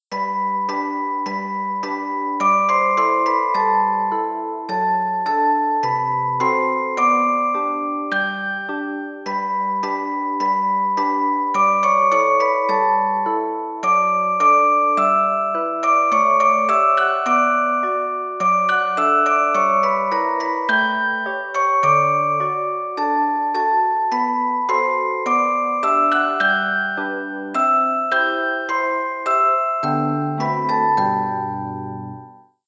動作確認放送